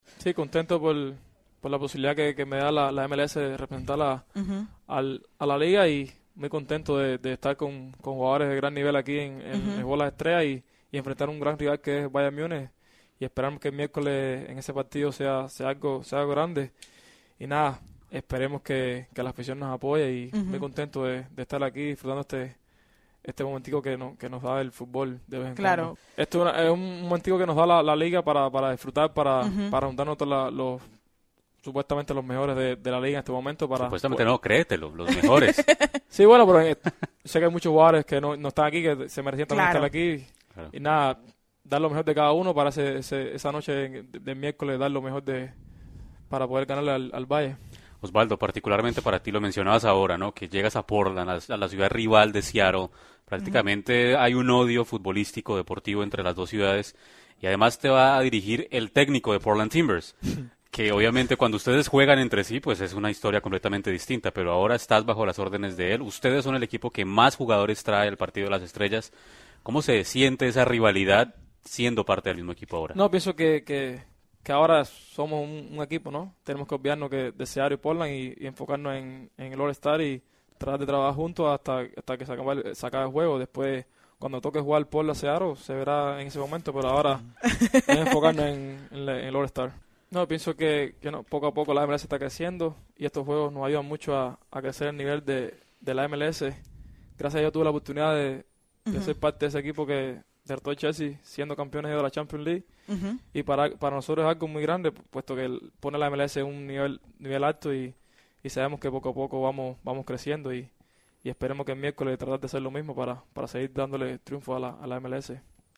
Entrevista a Osvaldo Alonso por Tiro Libre de la MLS